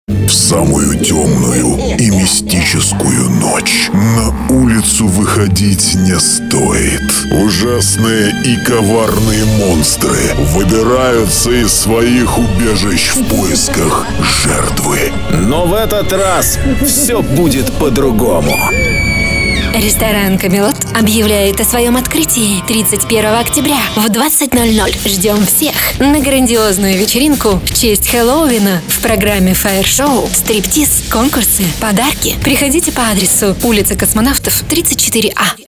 Аудиоролик Хэллоуин
Создание аудиоролика под ключ: сценарий+дикторы+эффекты, музыкальная подложка